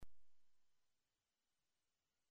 The piisa consists of two parts, firstly the metal bell which is worn over the middle finger, and secondly a metal ring which is worn around the thumb and strikes the metal finger bell.
The piisa has a penetrating timbre, which gives an extra dimension to the music.